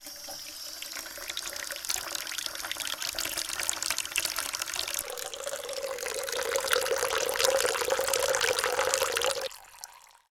bathroom-sink-01
bath bathroom bubble burp click drain dribble dripping sound effect free sound royalty free Sound Effects